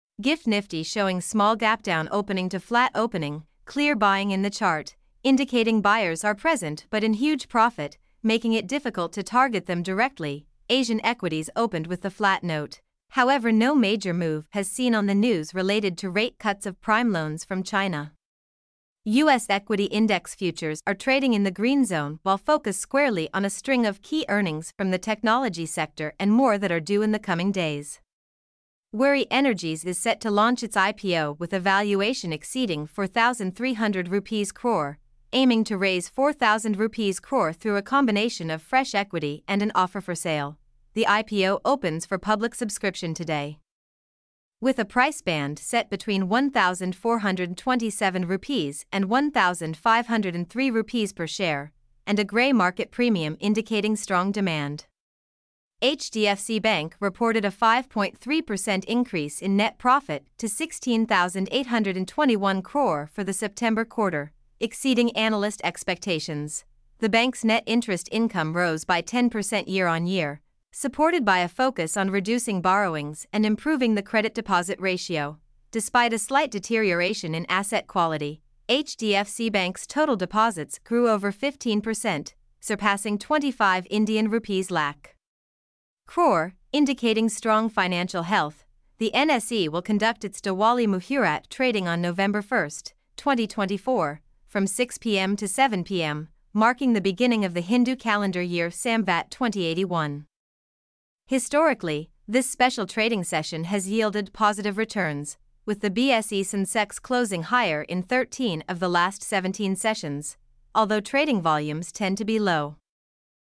mp3-output-ttsfreedotcom-12.mp3